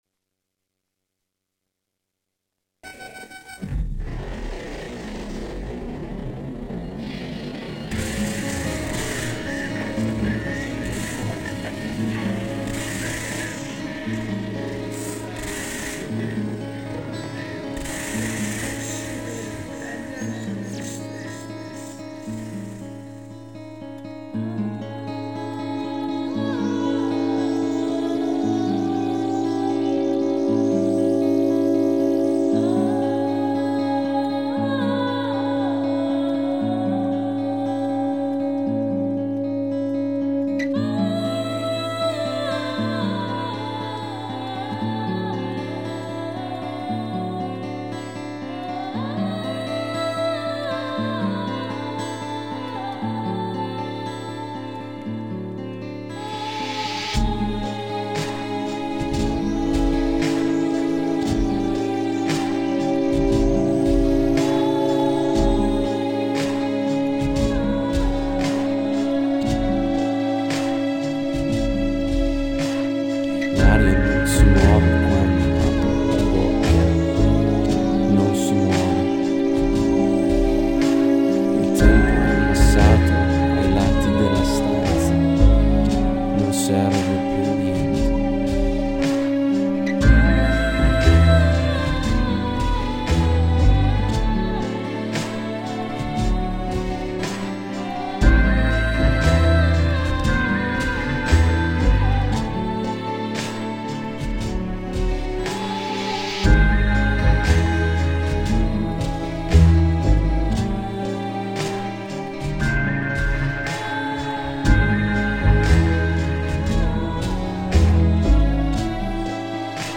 panorama di malinconia e vuoto esistenziale
Download in qualità CD